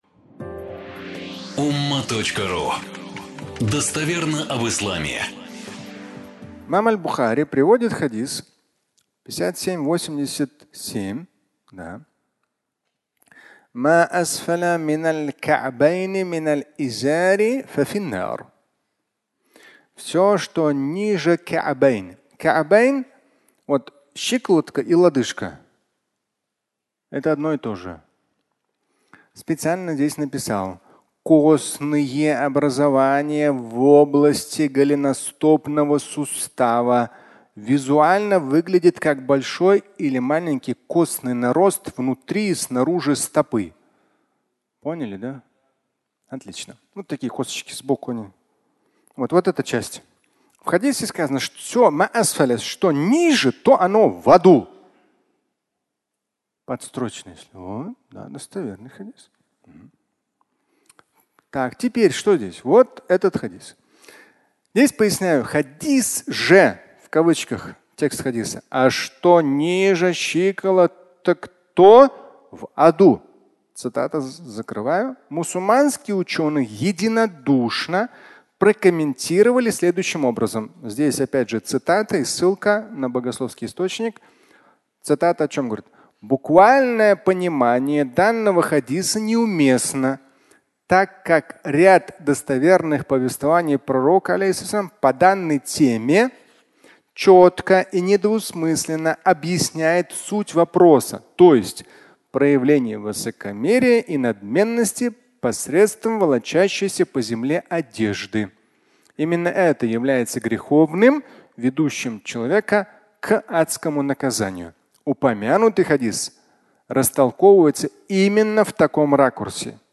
Фрагмент пятничной лекции
Пятничная проповедь